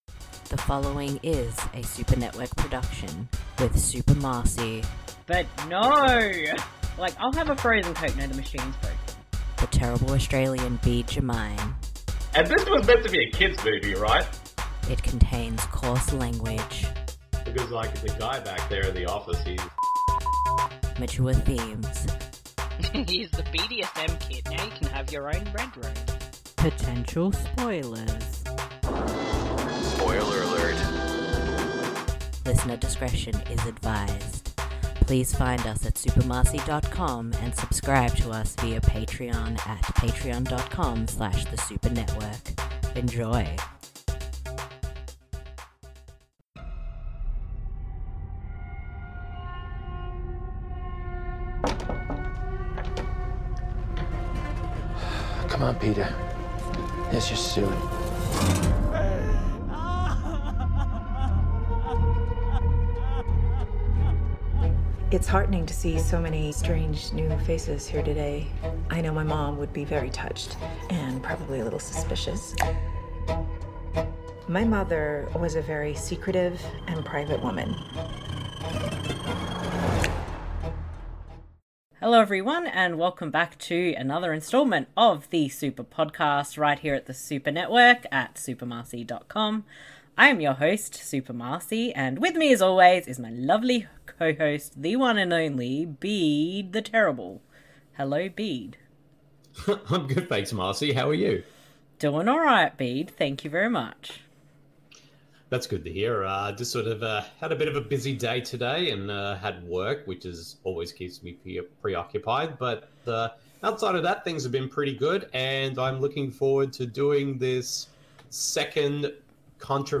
April's Fan Voted Audio Commentary Bonus Episode: Hereditary (2018)